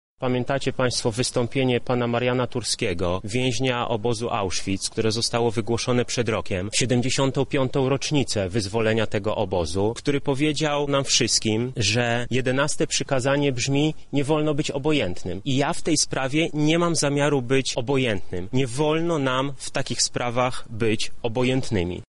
— mówi poseł Krawczyk.